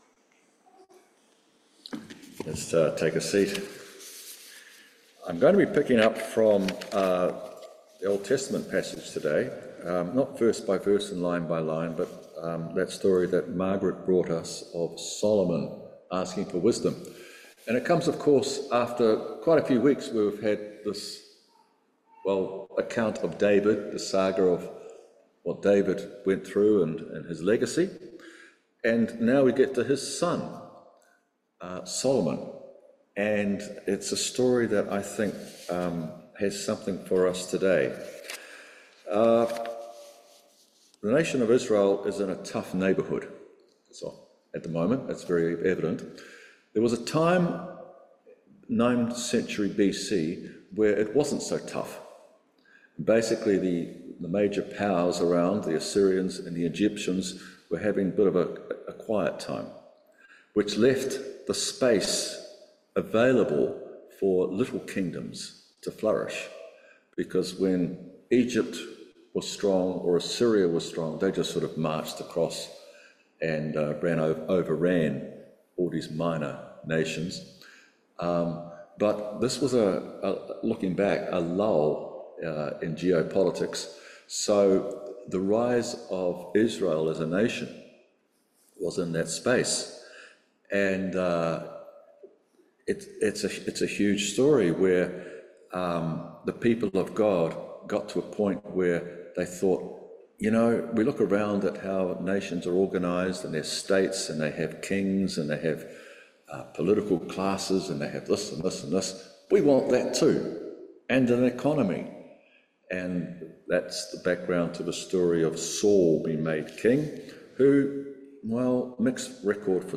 3:3-14 Service Type: Holy Communion What is the wisdom God gives?